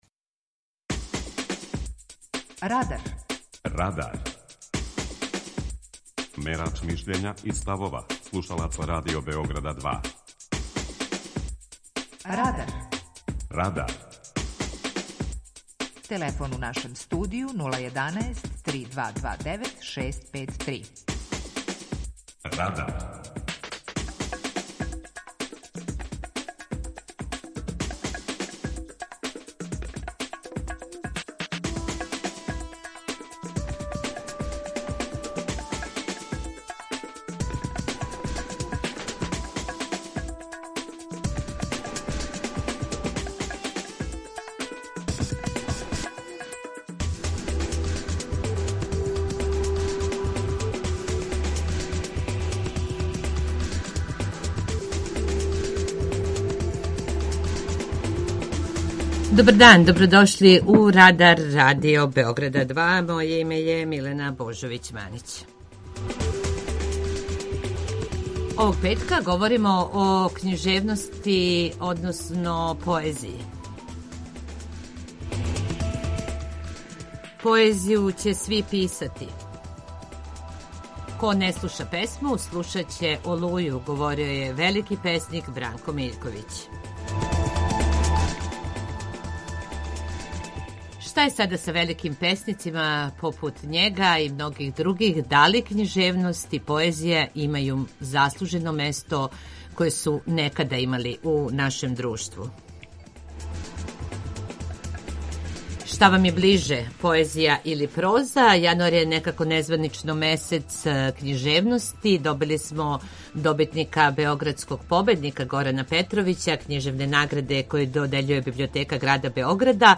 Питање Радара је: Шта Вам је ближе поезија или проза? преузми : 19.17 MB Радар Autor: Група аутора У емисији „Радар", гости и слушаоци разговарају о актуелним темама из друштвеног и културног живота.
У емисији ћемо слушати како стихове говоре наши велики песници Васко Попа и Милош Црњански.